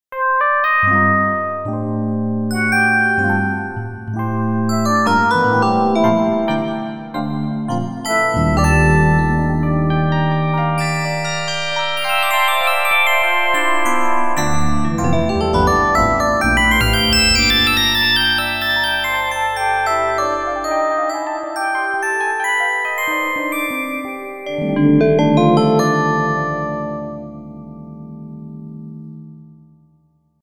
I made some example music with this setup, starting with a multitrack recording with rosegarden of some hexter sounds with ingenuity processing, and then combined like above with a combined hexter/PD sound with ingenuity coupled LADSPA effects, which is then input to rosegarden, and recorded on a new track.